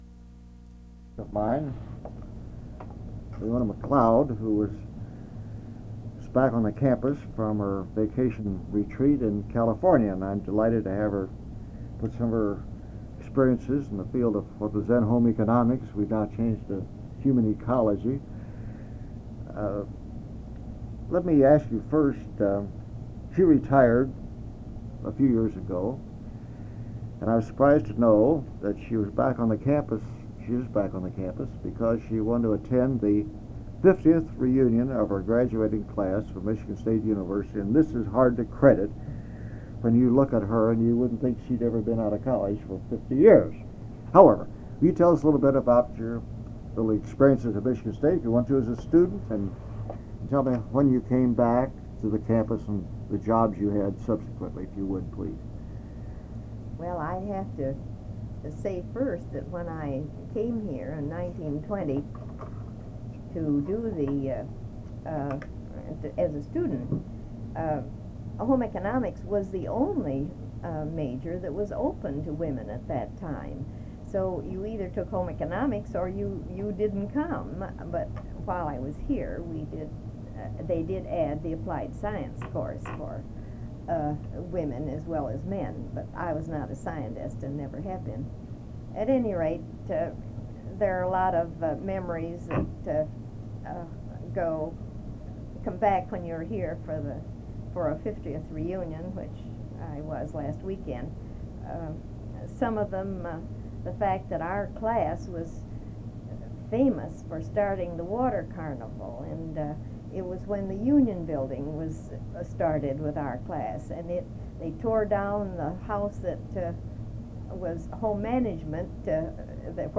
Interview
Original Format: Audio cassette tape